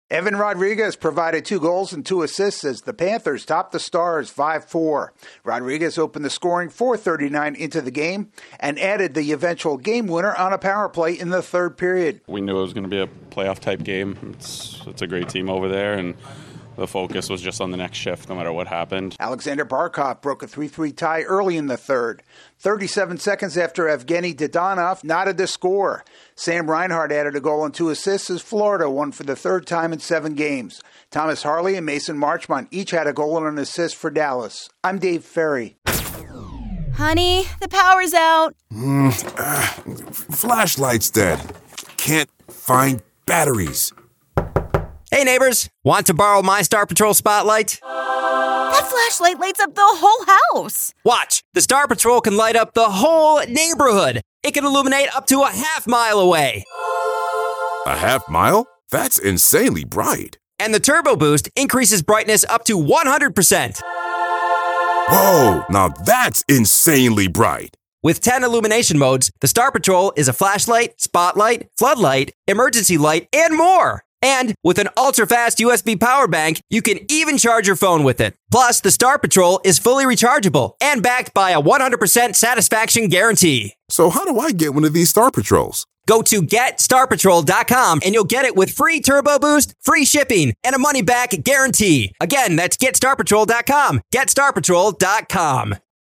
The Panthers win a scoring battle with the Stars. AP correspondent